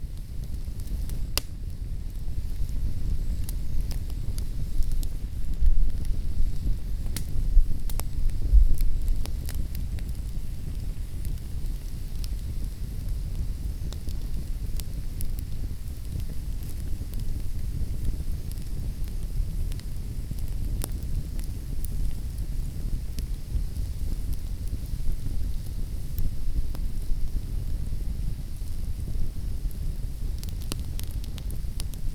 SmallFire.ogg